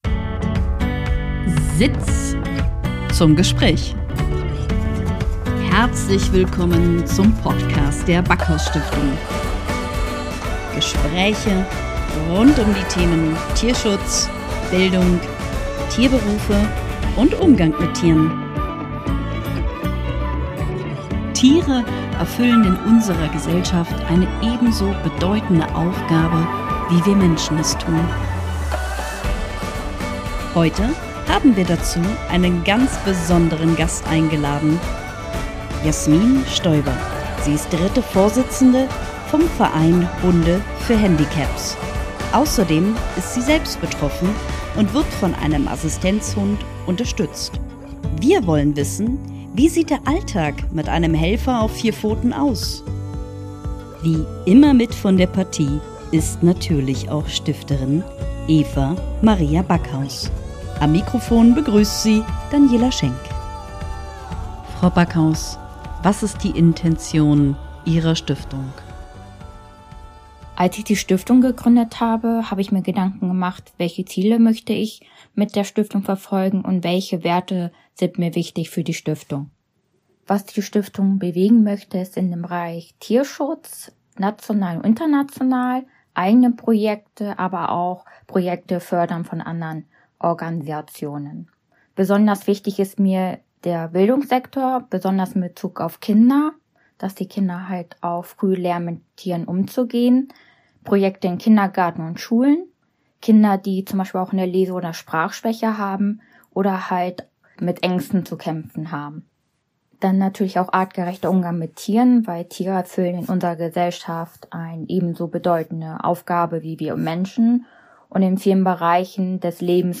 Beschreibung vor 3 Jahren Heute haben wir im Interview einen besonderen Gast